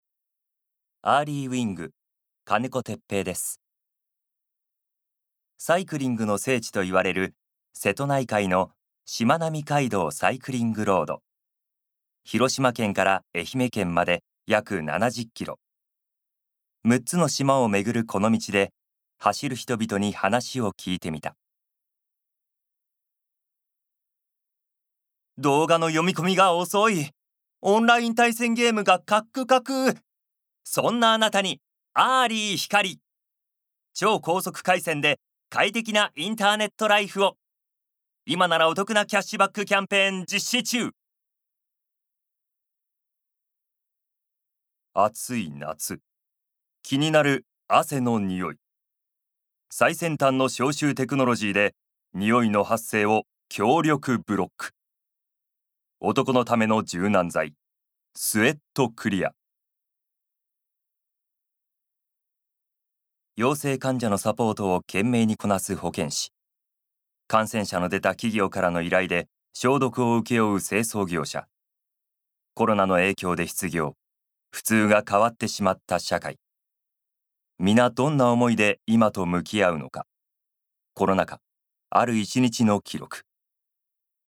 ボイスサンプル
ナレーションALL